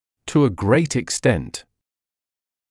[tuː ə greɪt ɪk’stent][туː э грэйт ик’стэнт]в большой степени; в значительной степени